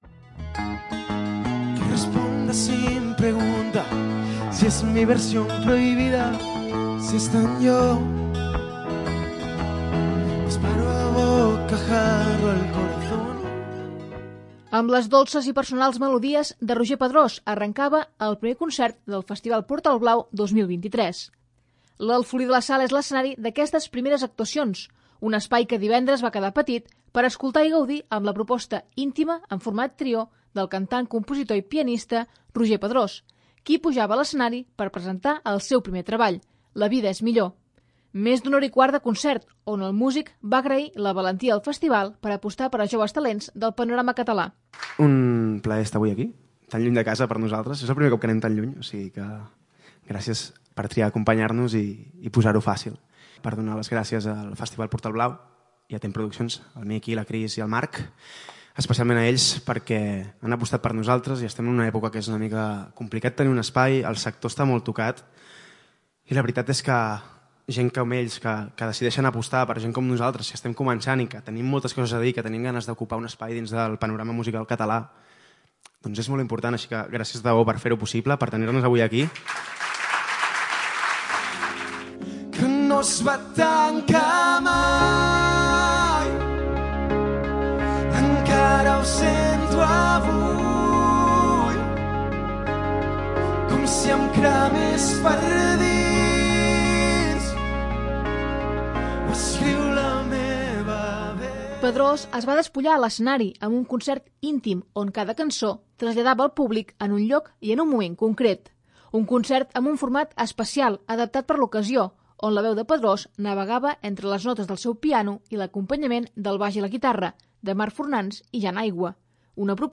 L'Informatiu
Amb les dolces i personals melodies